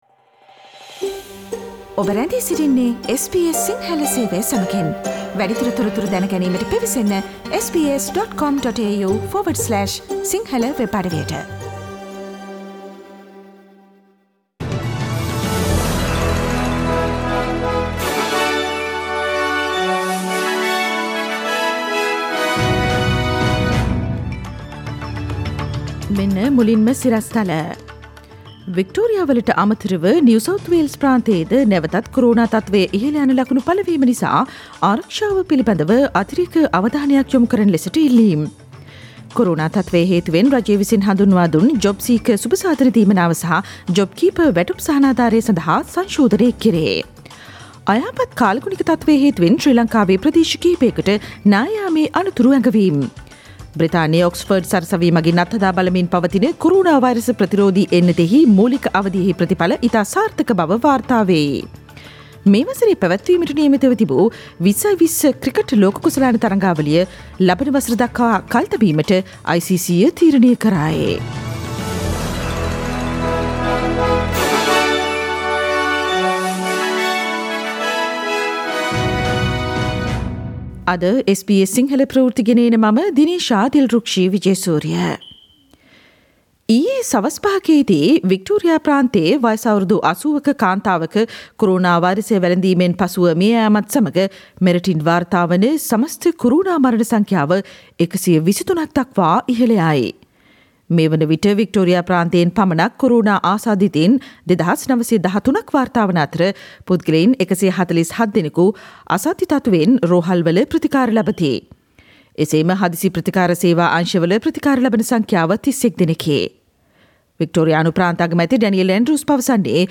Today’s news bulletin of SBS Sinhala radio – Tuesday 21 July 2020
Daily News bulletin of SBS Sinhala Service: Tuesday 21 July 2020.